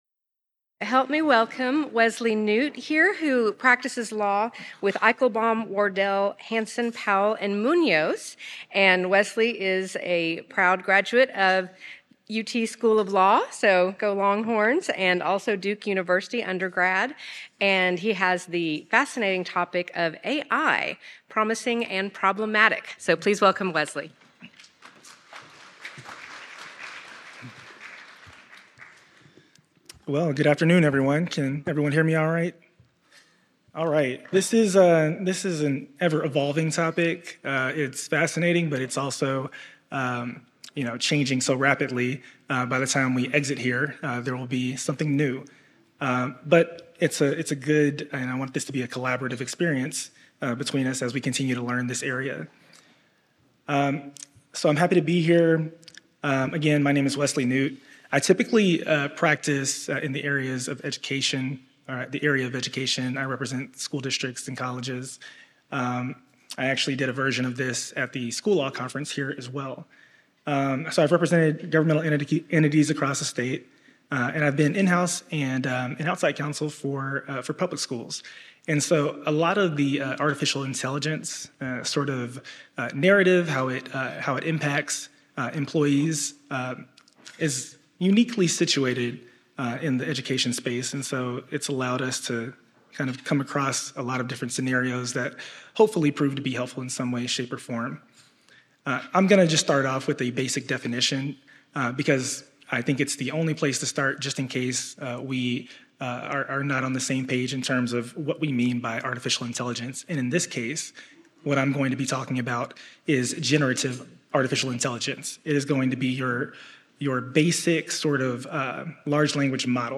Originally presented: May 2024 Labor and Employment Law Conference